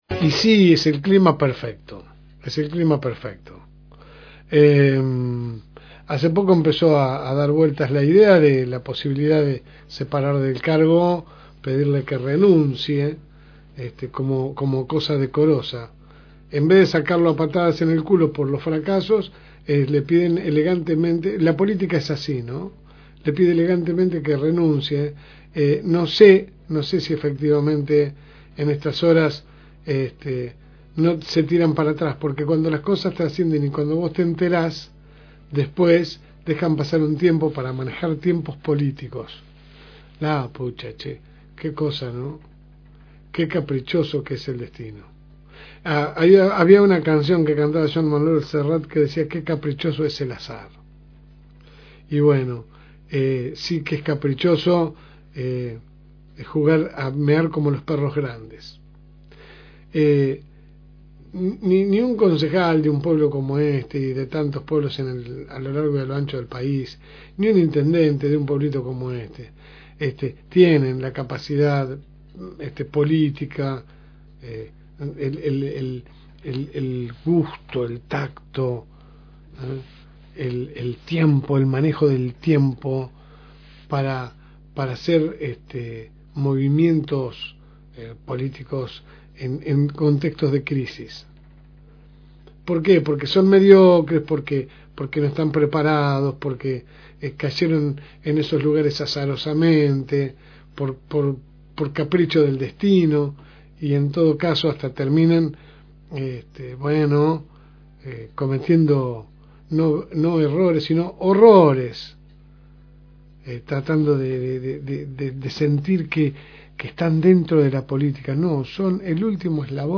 AUDIO – La editorial